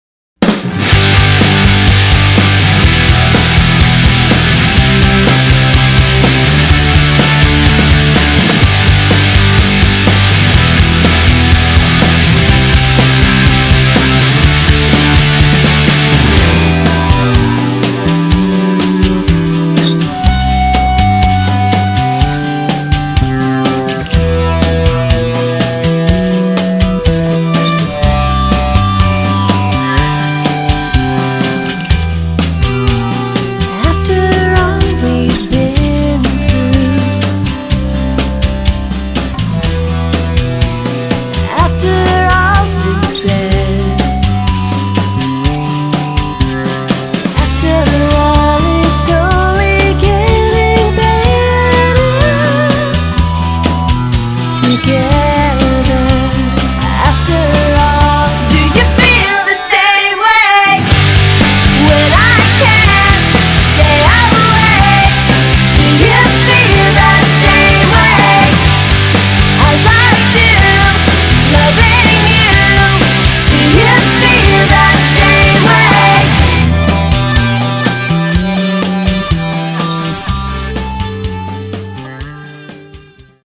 on drums
bass
percussion